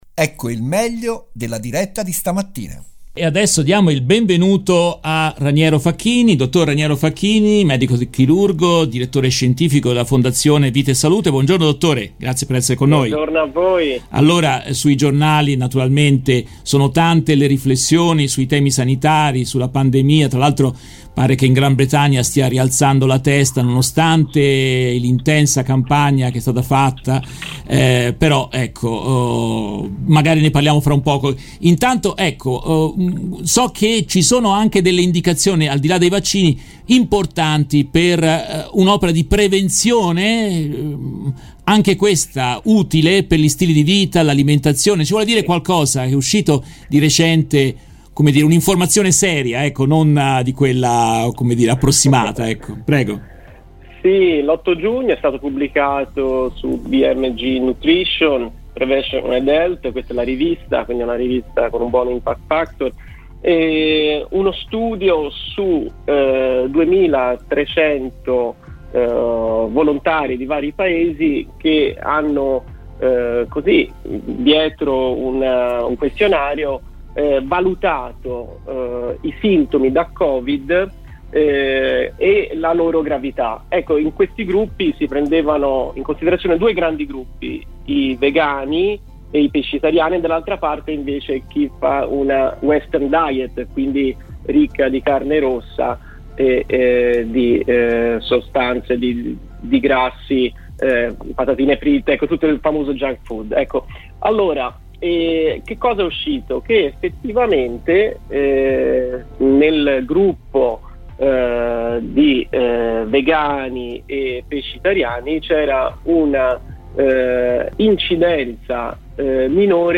In questa intervista tratta dalla diretta RVS del 7 giugno 2021